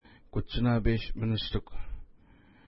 Pronunciation: ku:tʃəna:pes-ministuk
Pronunciation